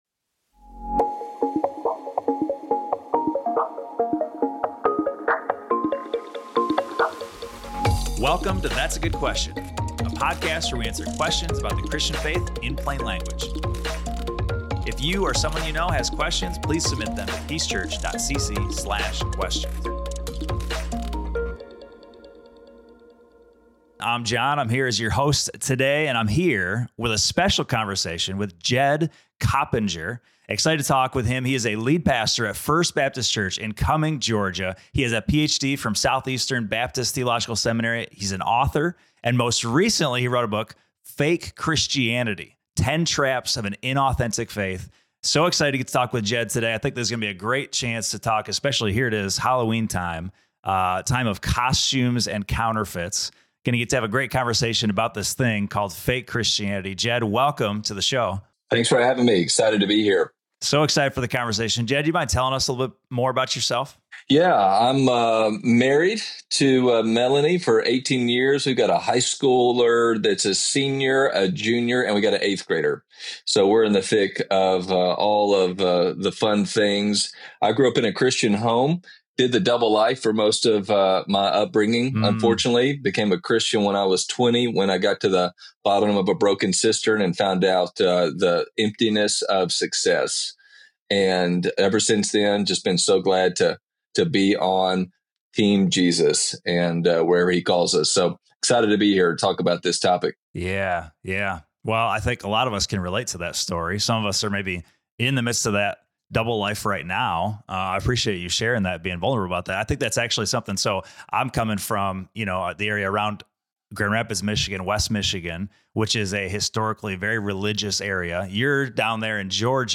Fake Christianity: A Conversation